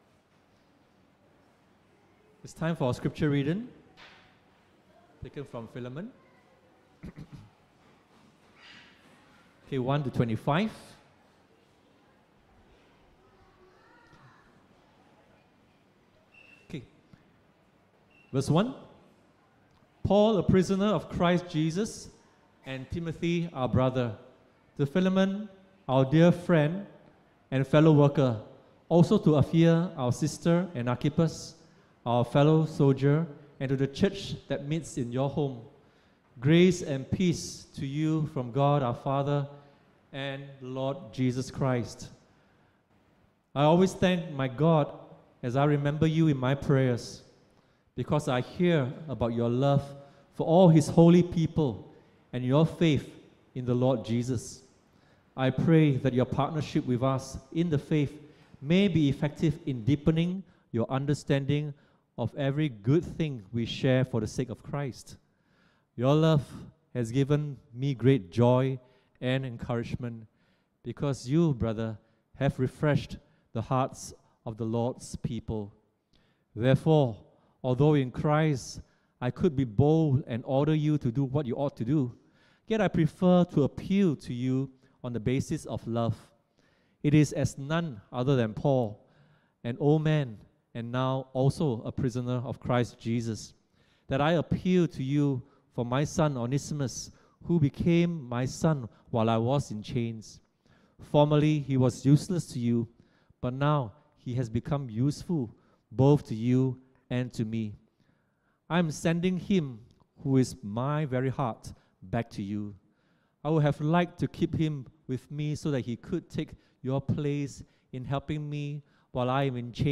21 April 2024 EC Sermon